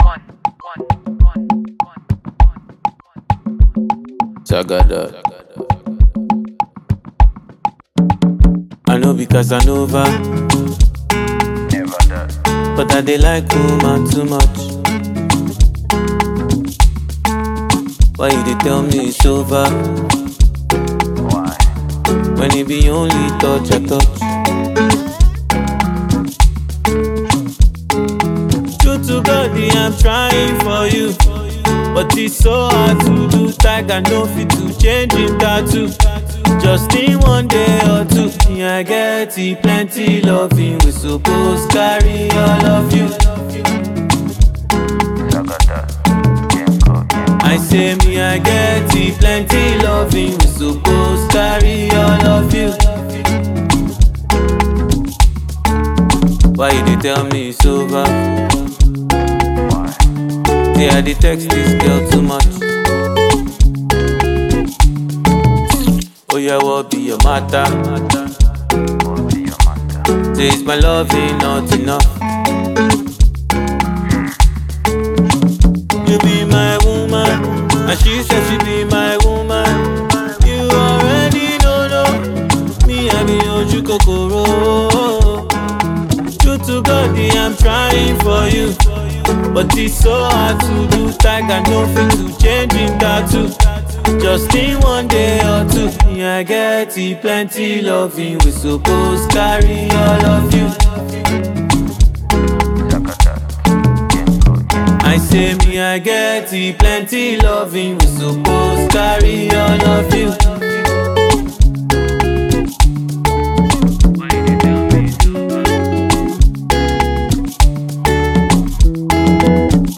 Afrobeats